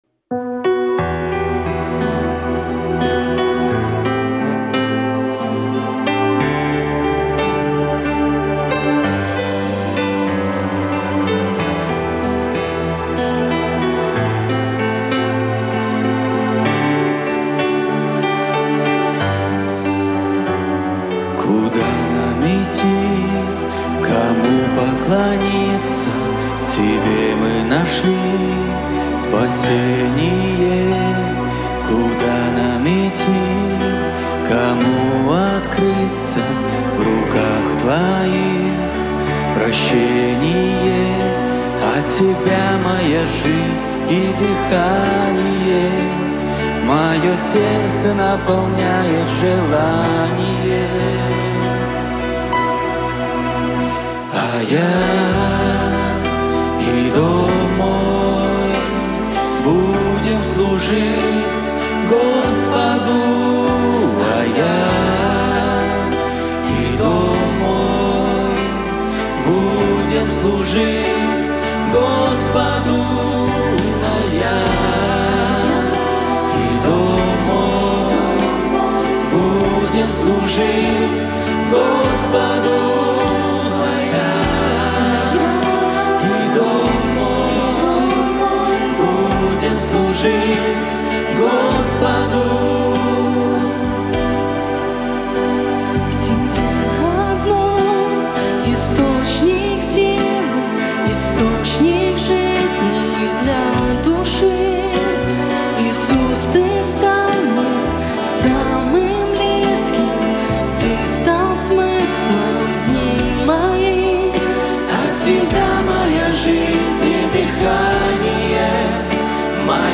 клавиши, гитара, вокал
Псалмы группы